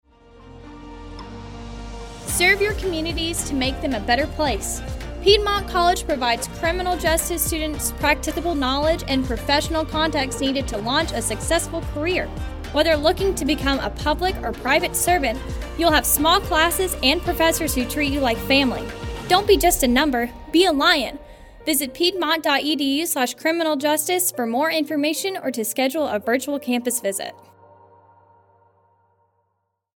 Criminal Justice Radio Spot